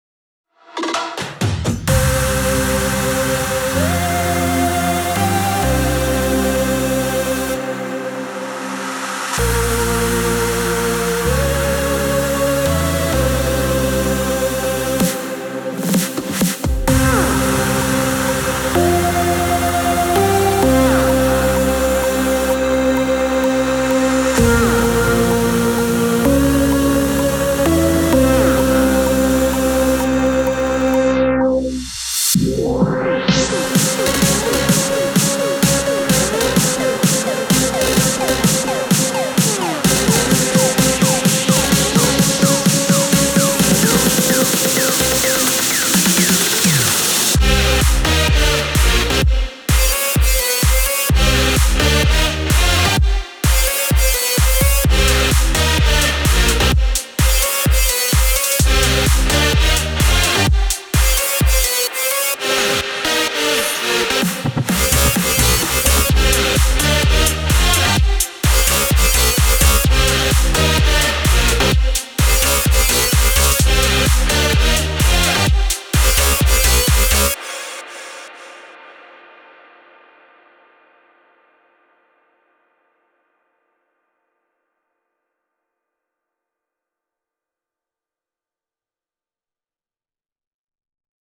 הכי אהבתי את הגיטרה ברקע
האוס.mp3 מוזיקה האוס { נראה לי שככה קוראים לזאנר}, תחקו לשנייה ה 55